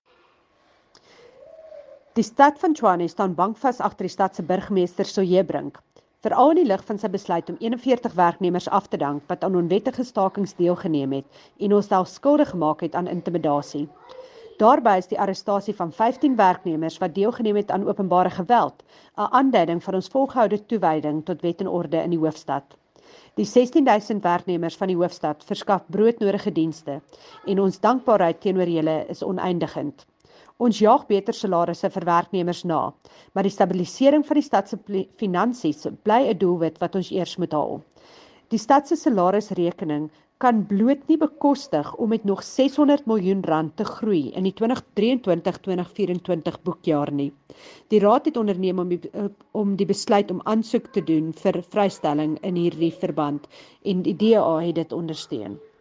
Please find soundbites by Cllr Jacquie Uys, Tshwane Caucus Chairperson in English